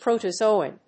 音節pro・to・zo・on 発音記号・読み方
/pròʊṭəzóʊɑn(米国英語), pr`əʊtəzˈəʊɔn(英国英語)/